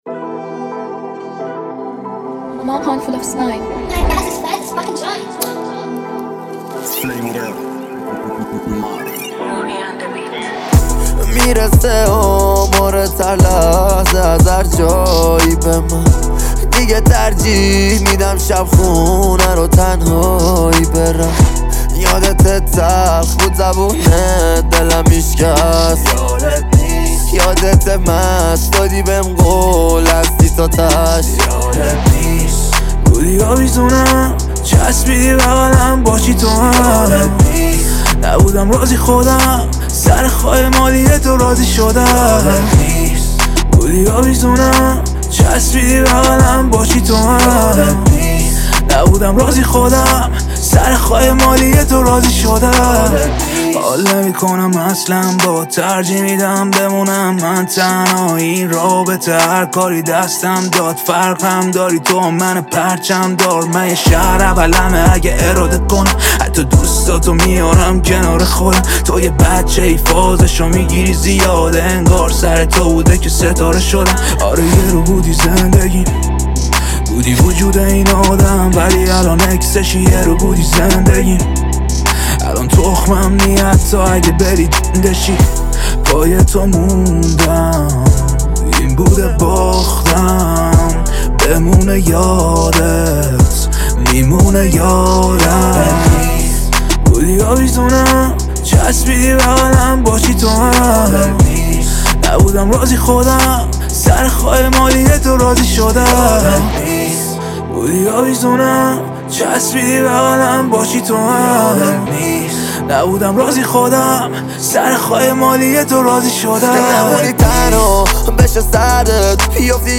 اهنگ جدید رپ